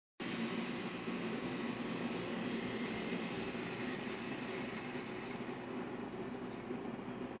Funny noise from P61A
Our P61A is making a noise we never heard before - it's not a loud sound - it kind of sounds like pellets going down a chute. It is quiet and then we hear this, it goes quiet and then starts again.